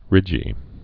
(rĭjē)